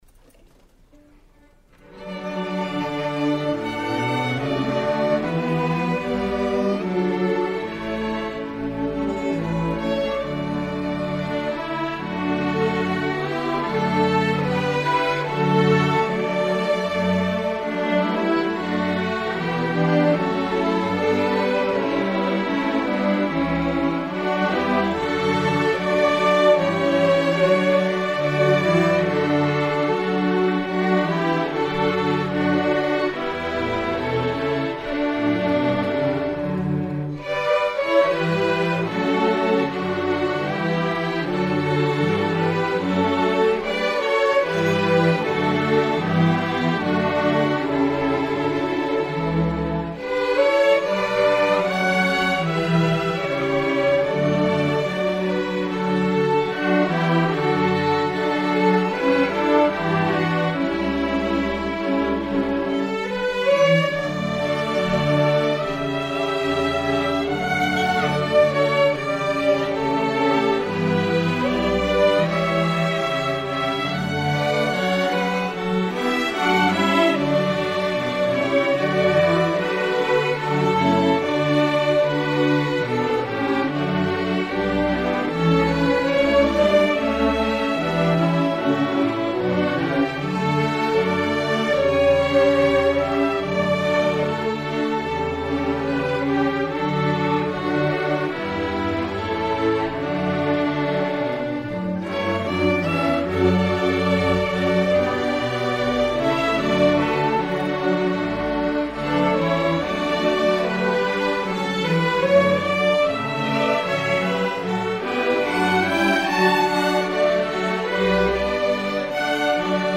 Queen City Community Orchestra
Spring 2019 Concert